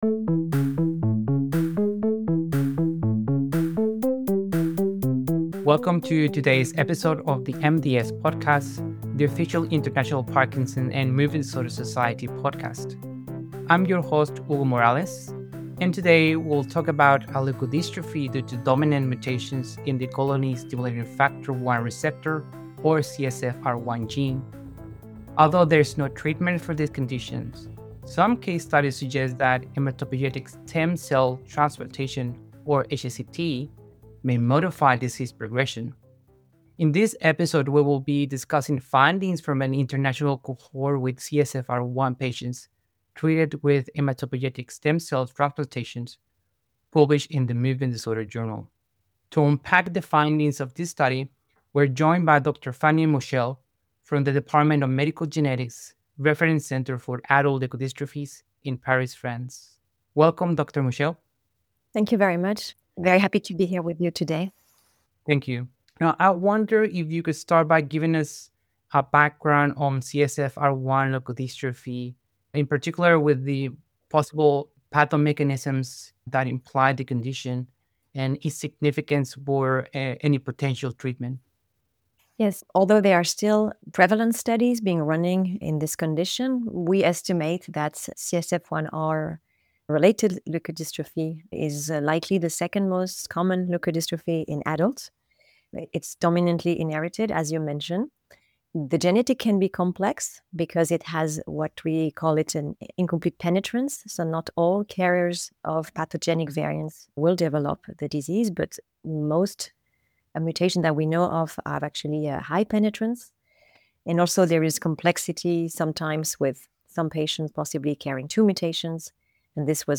Stay up to date on novel, clinically-relevant research findings in Parkinson's disease and other movement disorders. Each episode of the International Parkinson and Movement Disorder Society podcast discusses a relevant development in the field, including highlighted journal articles and interviews with the authors.